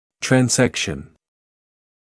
[træn’zekʃn][трэн’зэкшн]рассечение; поперечный разрез